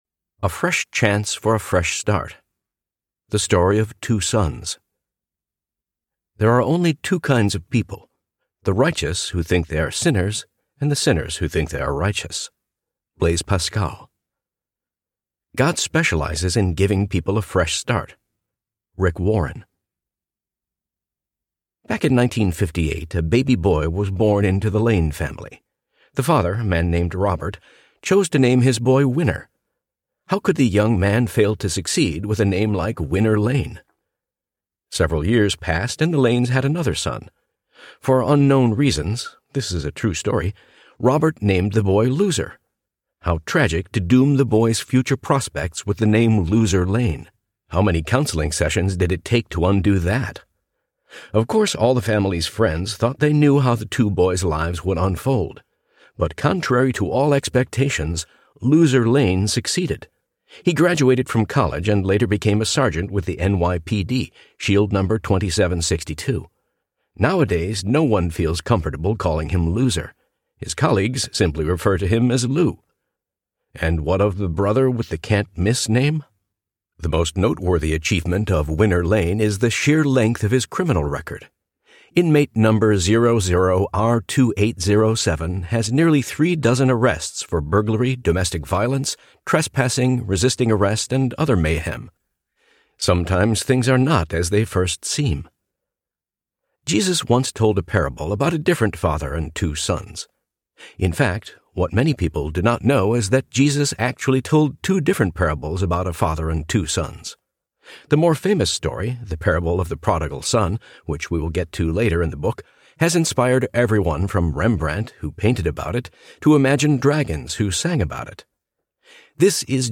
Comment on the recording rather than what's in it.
5.0 Hrs. – Unabridged